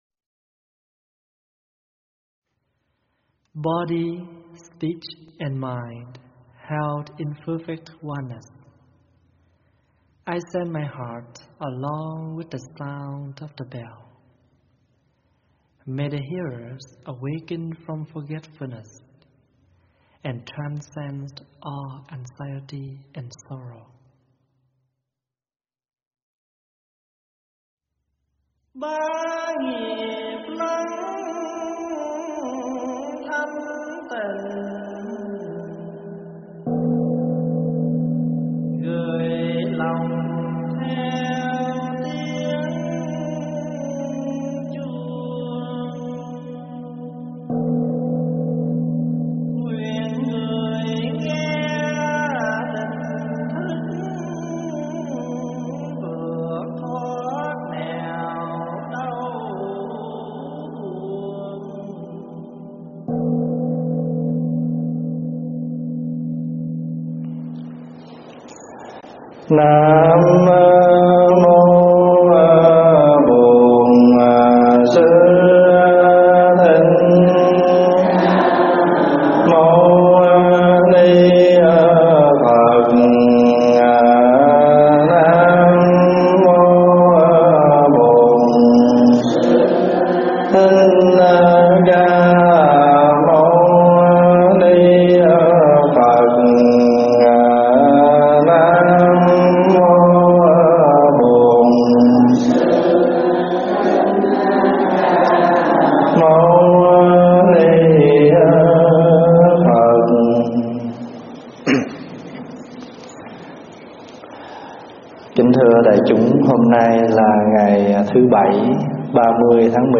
Mp3 thuyết pháp Vua - Sám Hối
giảng tại tu viện Tây Thiên